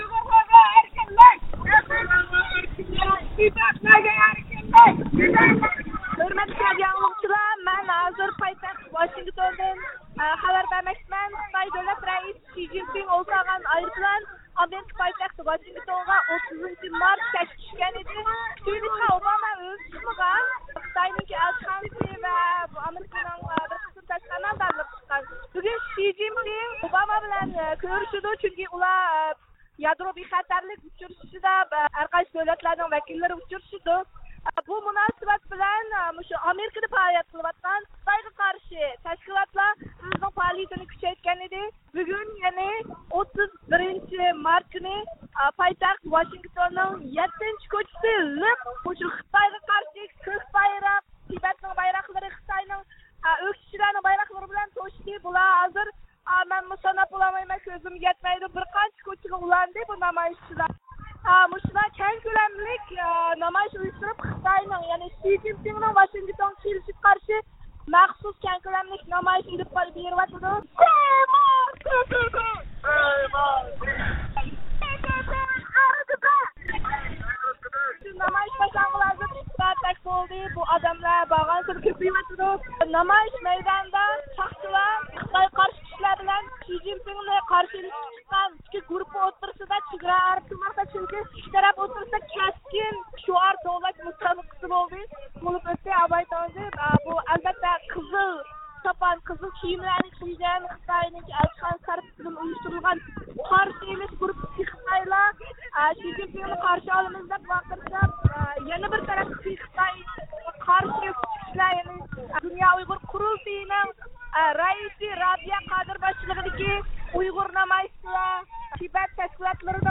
دۇنيا ئۇيغۇر قۇرۇلتىيى رەئىسى رابىيە قادىر خانىم نامايىش داۋامىدا مۇخبىرىمىزنىڭ نەق مەيدان زىيارىتىنى قوبۇل قىلدى. ئۇ سۆزىدە، ئوباما ھۆكۈمىتىنى شى جىنپىڭ بىلەن كۆرۈشكەندە ئۇيغۇر كىشىلىك ھوقۇق مەسىلىسىنى ئوتتۇرىغا قويۇشقا چاقىردى.